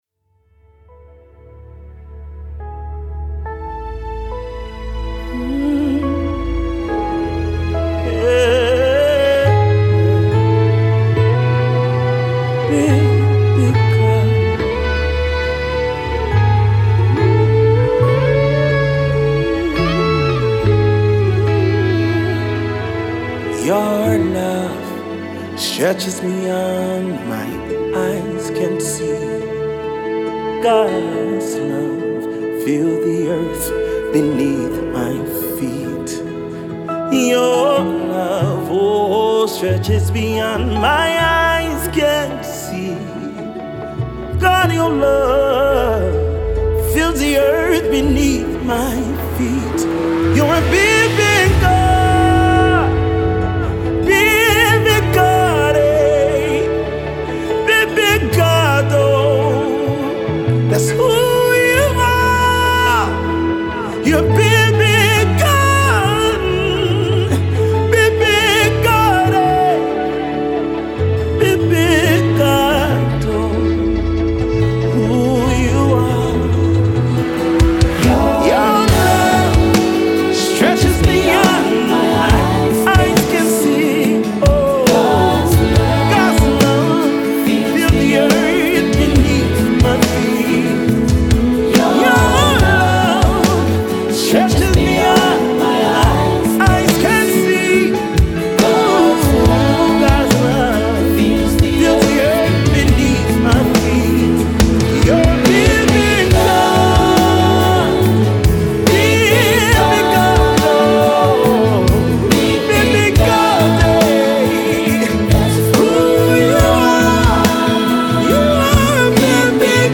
Contemporary Worship